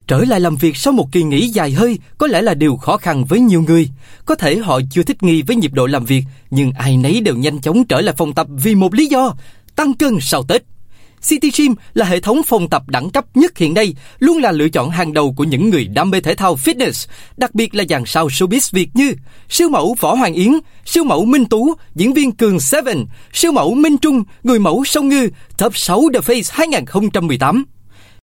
Voice Samples: Voice Sample 05
male